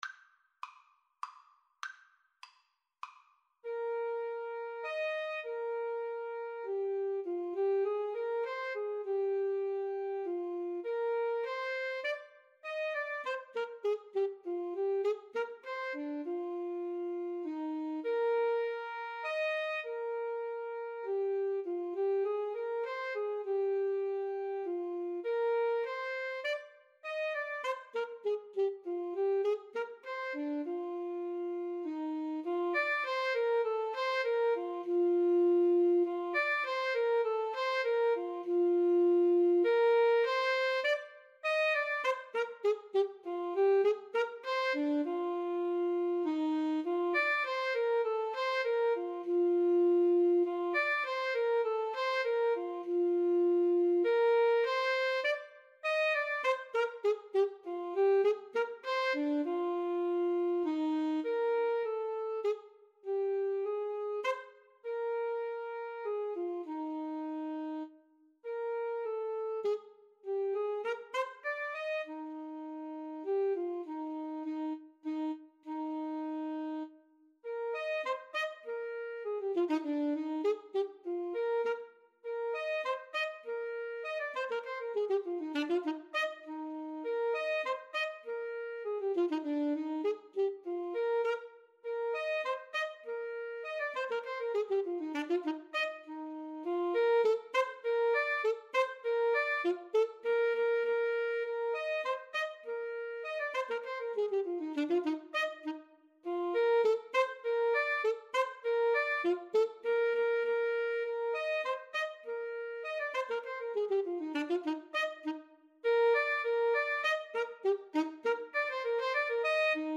Eb major (Sounding Pitch) F major (Clarinet in Bb) (View more Eb major Music for Clarinet-Saxophone Duet )
3/4 (View more 3/4 Music)
Cantabile
Classical (View more Classical Clarinet-Saxophone Duet Music)